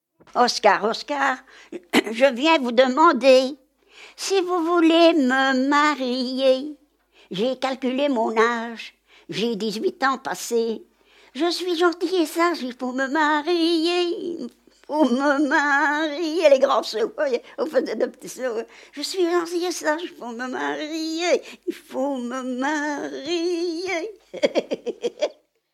Genre : chant
Type : chanson narrative ou de divertissement
Lieu d'enregistrement : Vierves-sur-Viroin
Support : bande magnétique